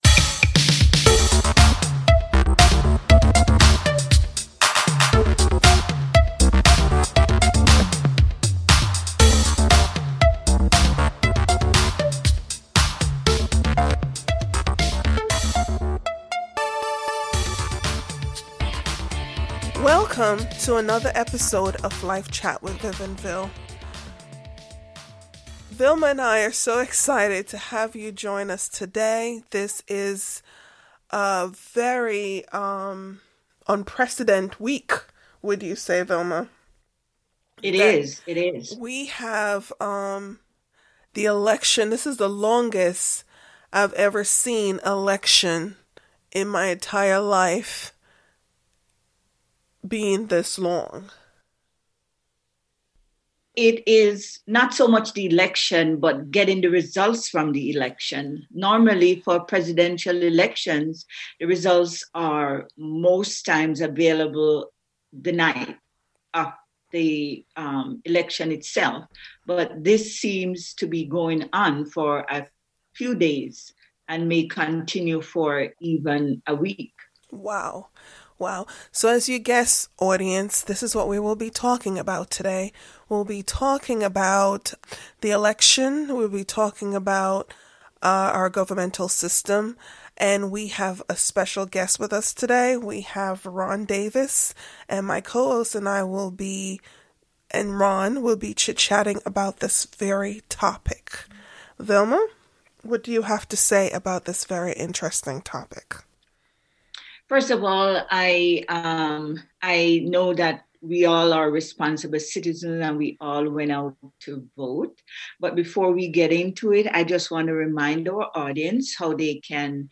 The hosts and guest talked about as Christians where our focus should be.